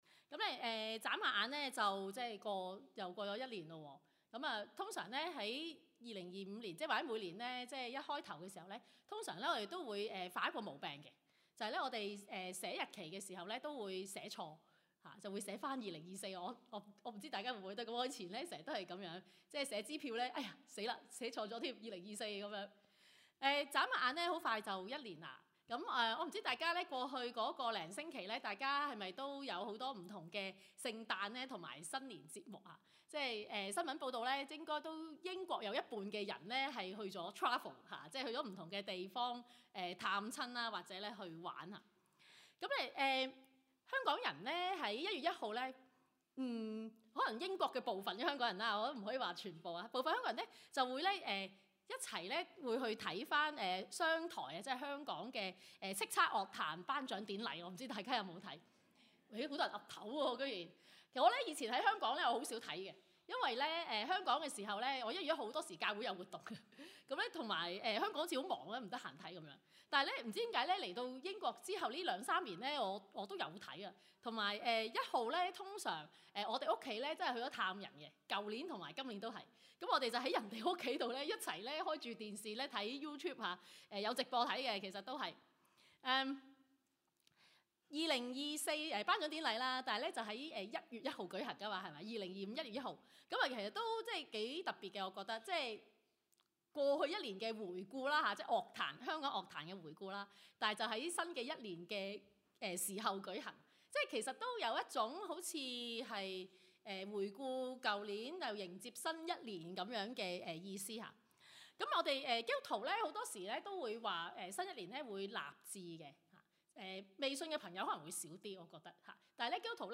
路加福音 24:13-35 Service Type: 粵語崇拜